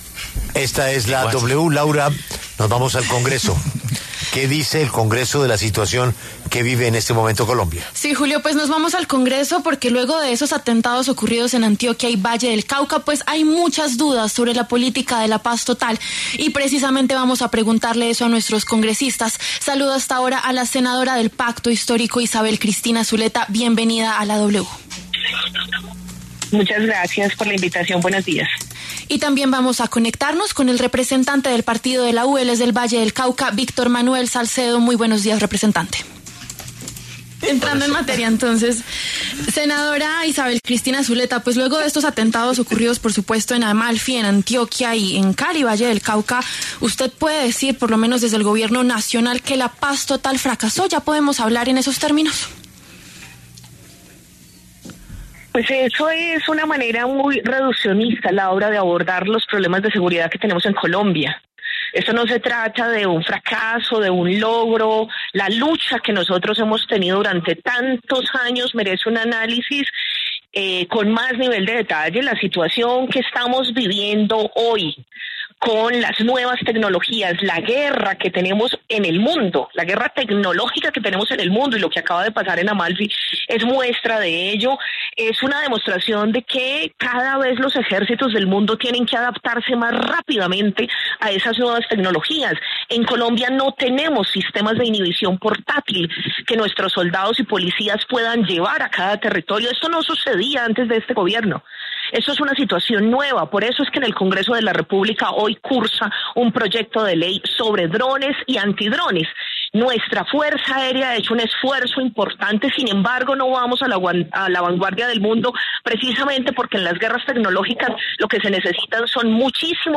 La senadora antioqueña Isabel Cristina Zuleta, del Pacto Histórico, y el representante vallecaucano Víctor Manuel Salcedo, del Partido de La U, pasaron por los micrófonos de La W.